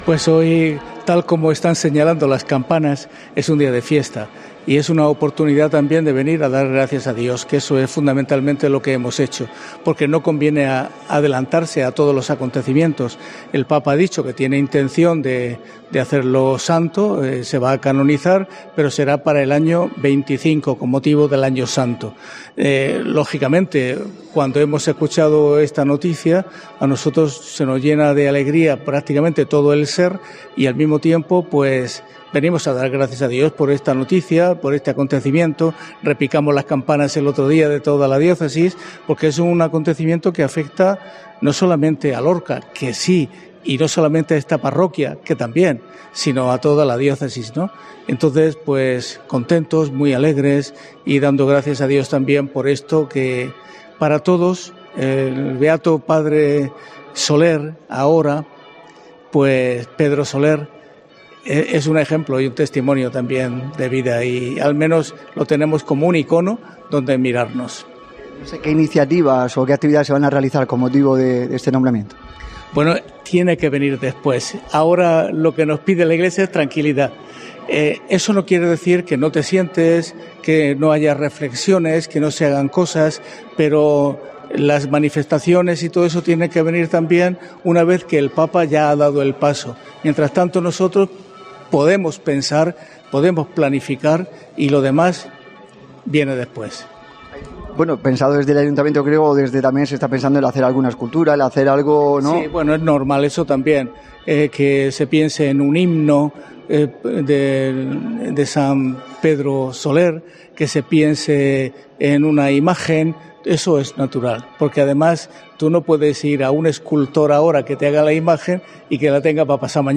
Monseñor Lorca Planes oficia la misa de acción de gracias por el Beato Pedro Soler